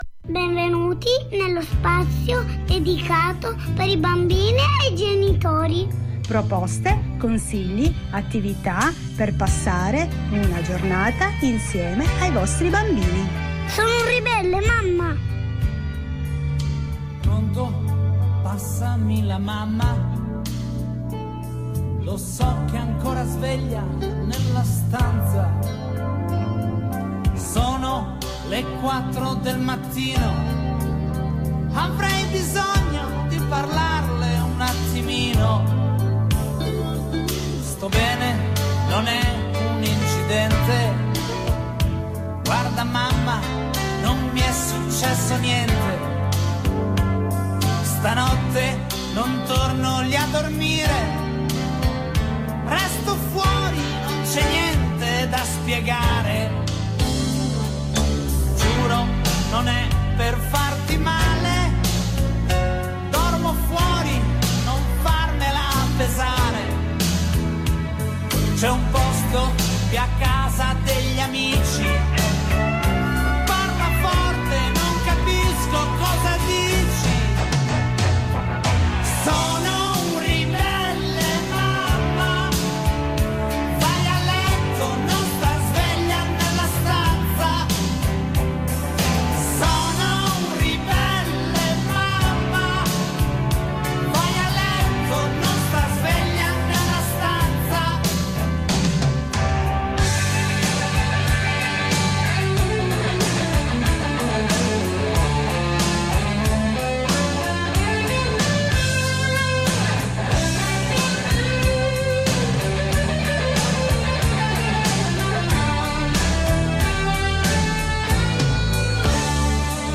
La lettura di oggi Il dragone puzzone Approfondiamo gli autori Pef e Henriette Bischonnier